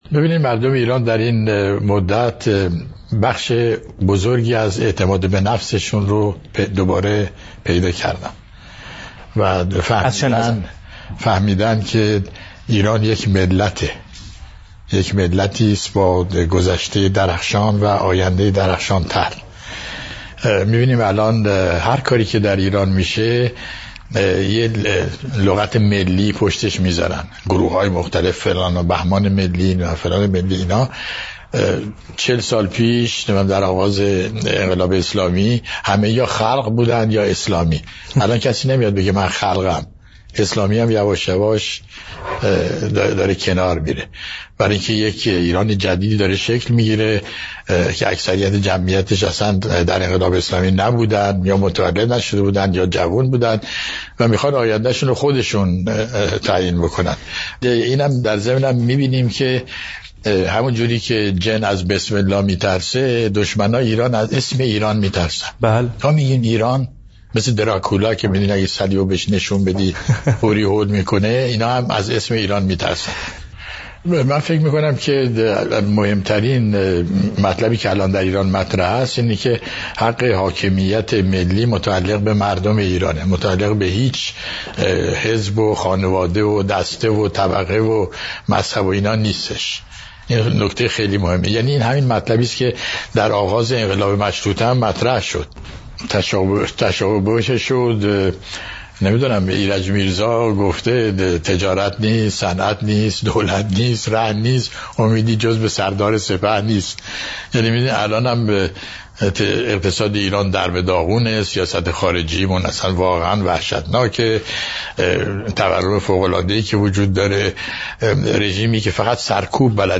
در این ده دقیقه، با کیفیت بسیار خوب صدا، استاد به روشنگری ناب پیرامون نکات زیر و بیشتر می‌پردازند. مردم ایران بخش بزرگی از اعتماد به نفس خویش را دوباره باز یافته‌اند…